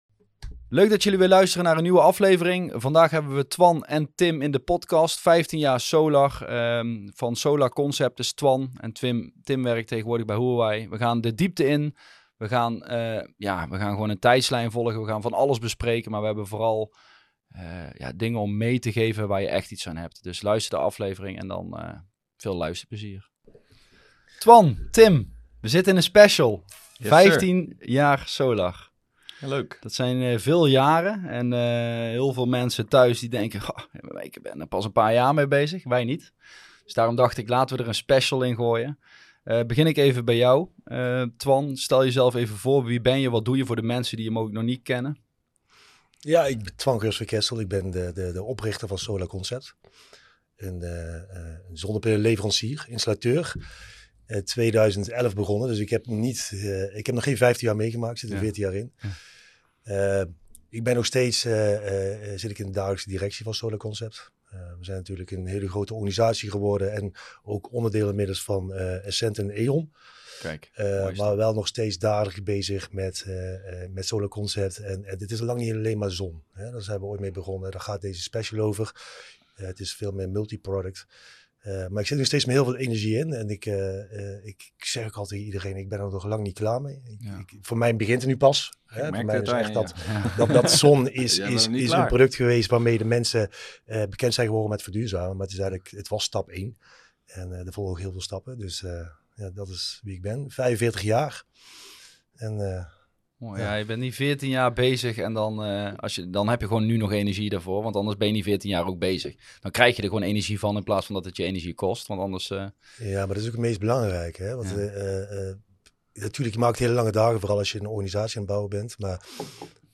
Bij Warmbatt de Potkwast vertalen we duurzaamheid naar begrijpelijke taal – geen ingewikkelde praatjes, maar eerlijke gesprekken met een vleugje humor en een flinke scheut enthousiasme.